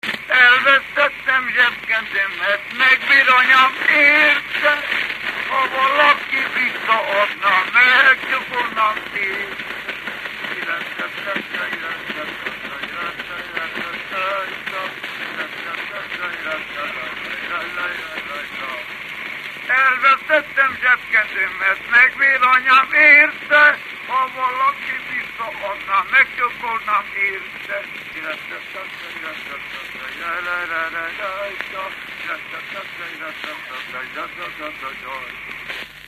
Erdély - Kolozs vm. - Méra
Stílus: 7. Régies kisambitusú dallamok
Kadencia: 9 (5) 5 1